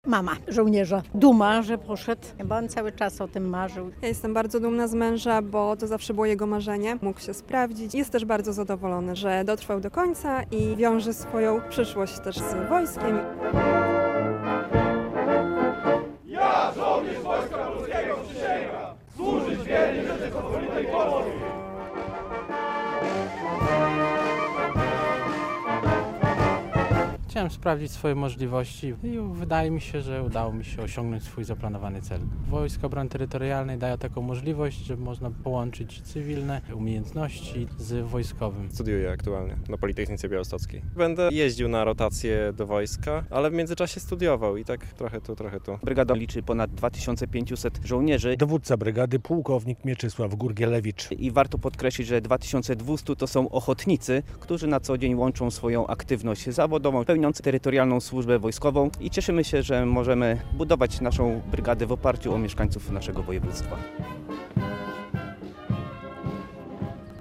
Przysięga żołnierzy WOT w Zambrowie